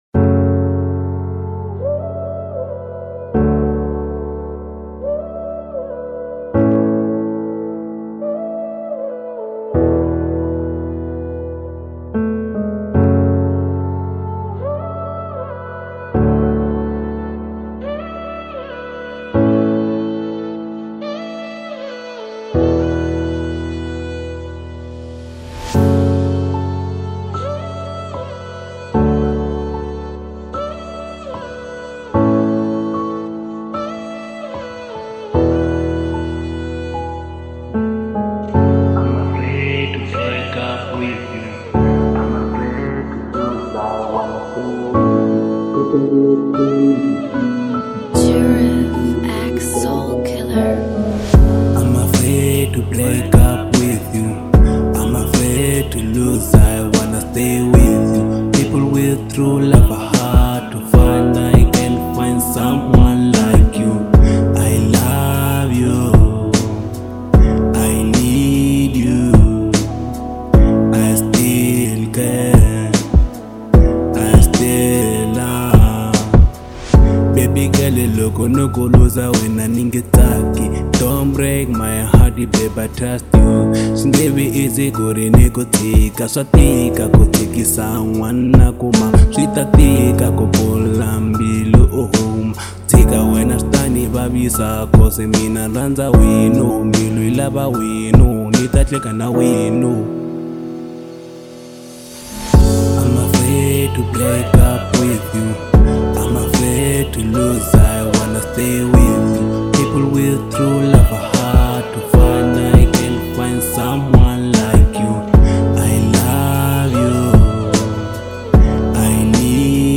03:30 Genre : Hip Hop Size